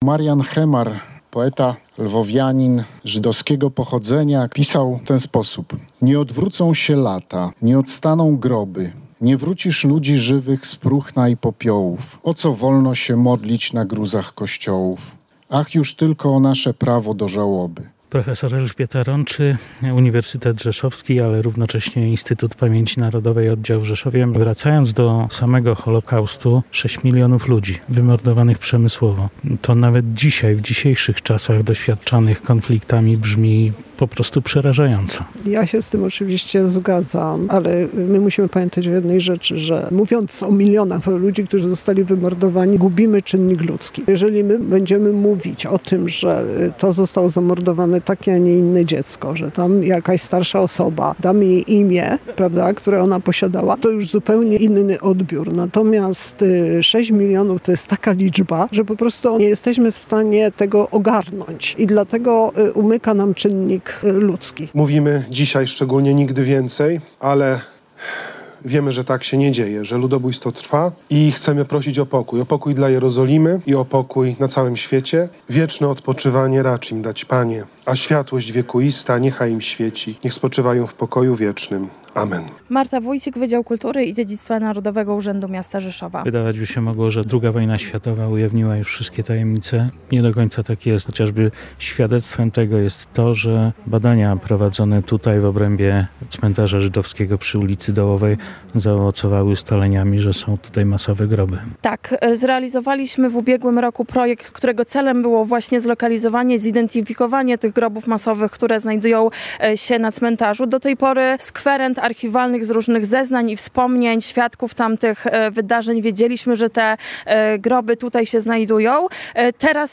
Relacje reporterskie • Na Cmentarzu Żydowskim przy ul.
Symboliczna ceremonia na Cmentarzu Żydowskim
Podczas uroczystości przy pomniku odczytano psalm oraz złożono wieńce i kamyki, które w tradycji żydowskiej symbolizują pamięć, szacunek i wieczną obecność.